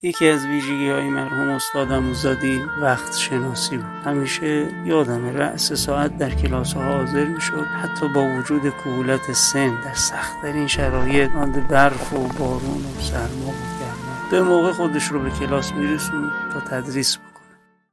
بیان خاطره‌ای